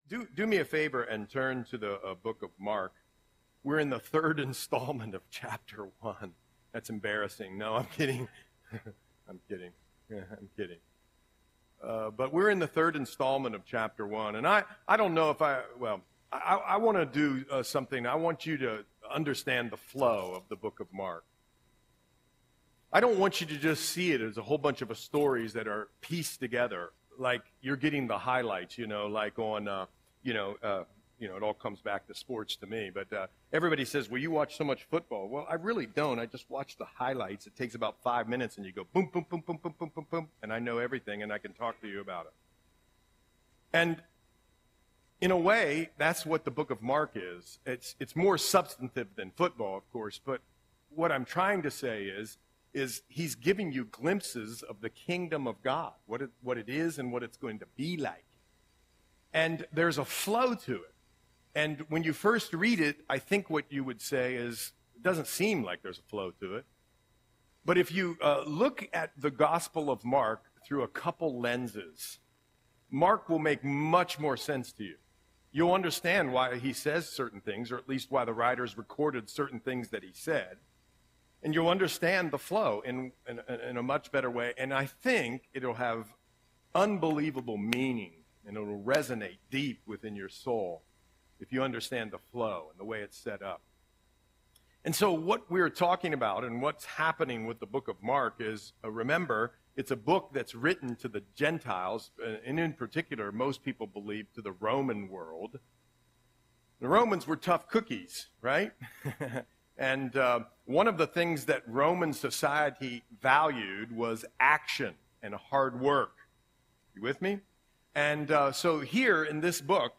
Audio Sermon - October 20, 2024